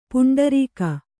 ♪ puṇḍarīka